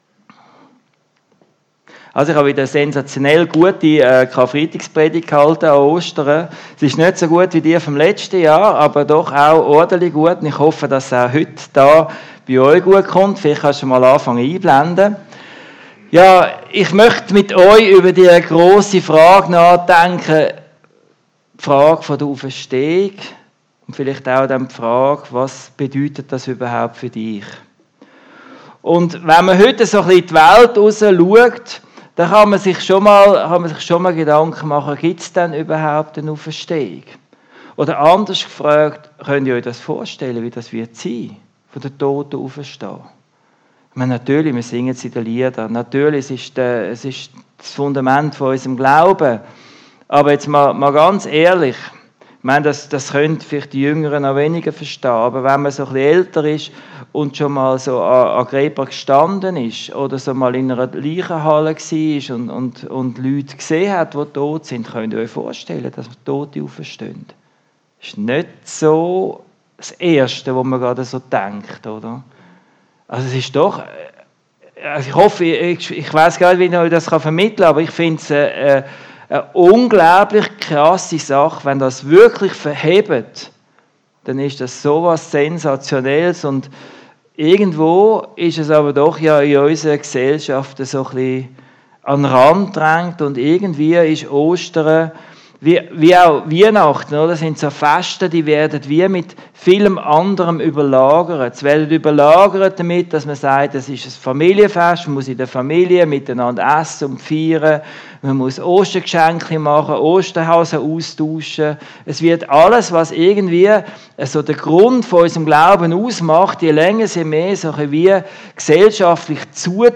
Predigt zu Ostern nach Matthäus 22, 23-33